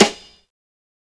SNARE_STRING_REV.wav